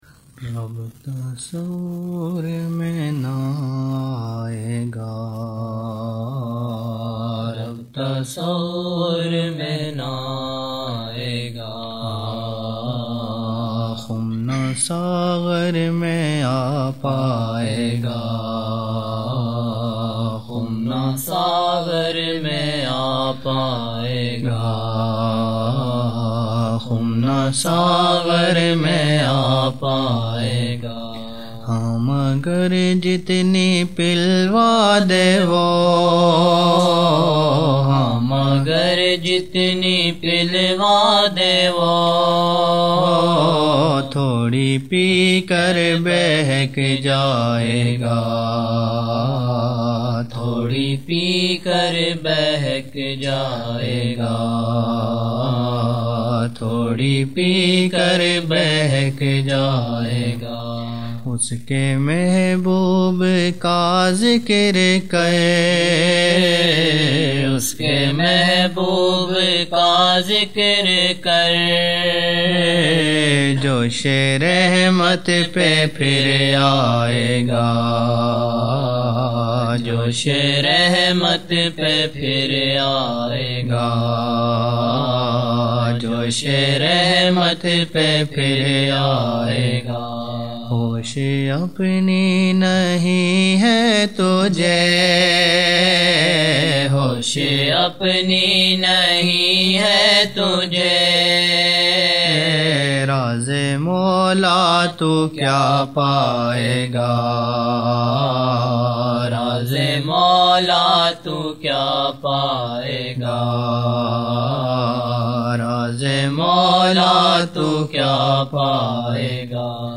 27 November 1999 - Isha mehfil (19 Shaban 1420)